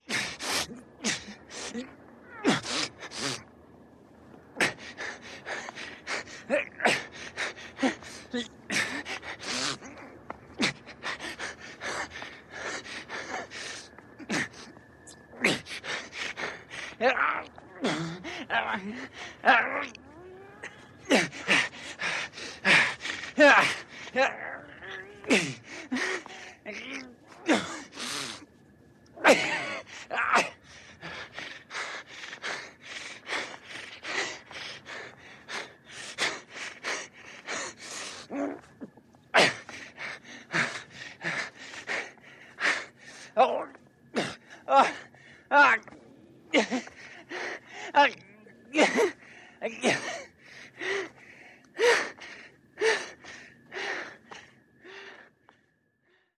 Звуки напряжения от тяжести
Звуки напряжения при подъеме тяжестей: дыхание человека во время усилия